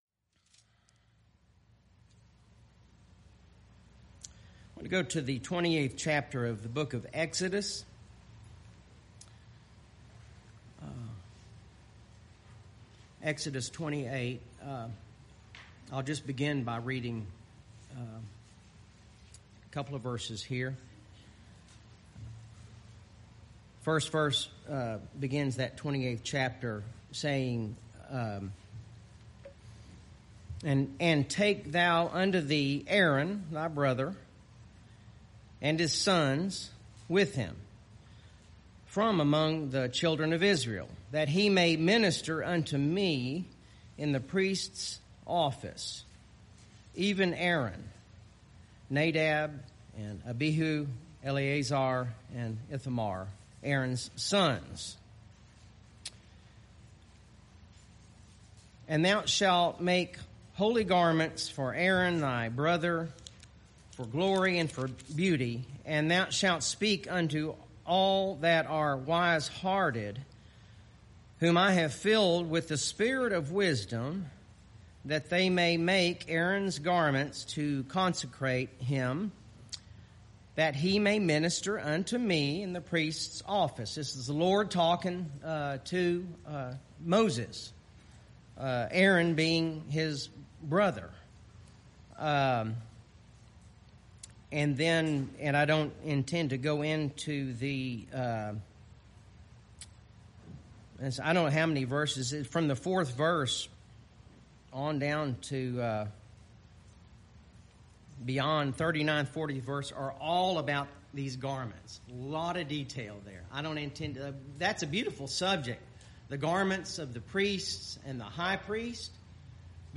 BHPBC Sermon podcast